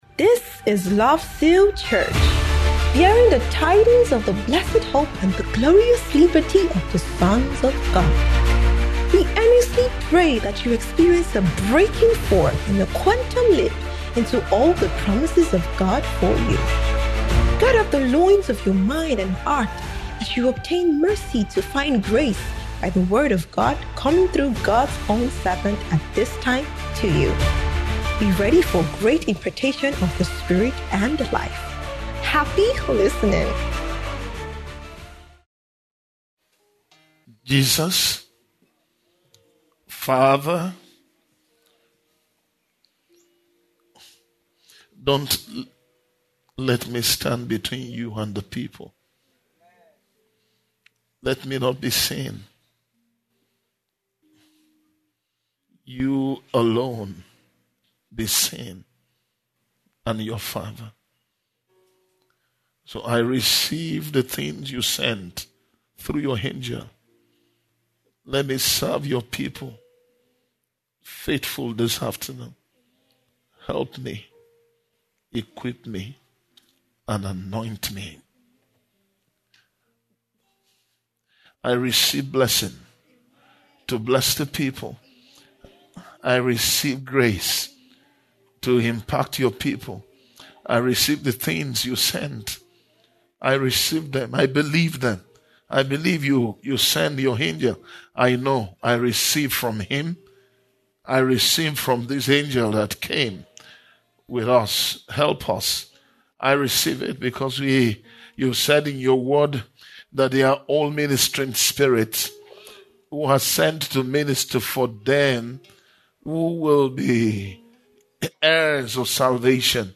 SUNDAY APOSTOLIC BREAKTHROUGH IMPARTATION SERVICE